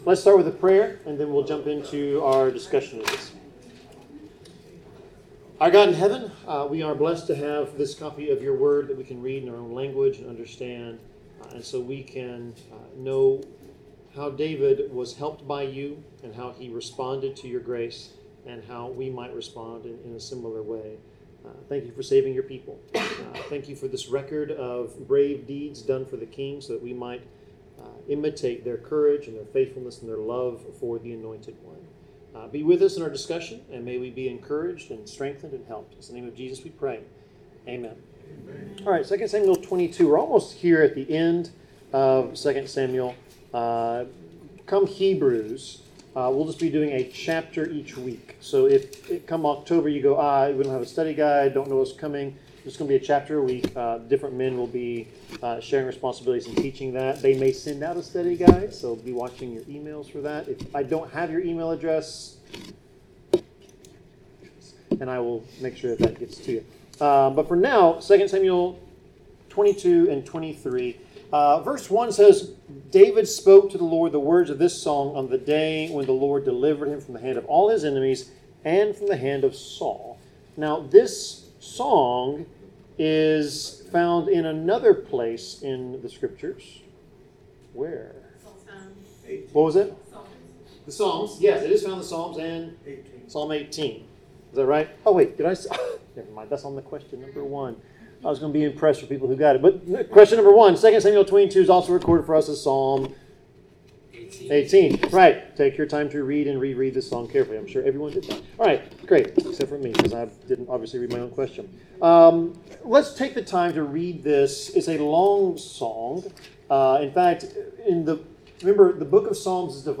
Service Type: Bible Class Topics: Deliverance , Devotion to God , God's Glory , God's Power , God's Protection , Love , Praise , Righteousness , Salvation , Worshipping God